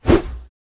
swing2.wav